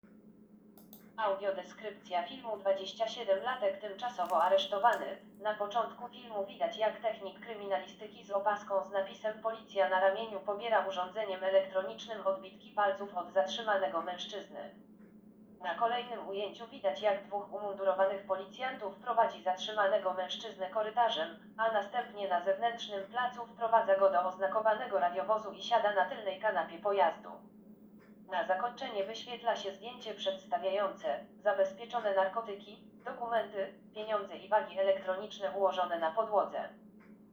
Nagranie audio Audio_deskrypcja_filmu_27-latek_tymczasowo_aresztowany.m4a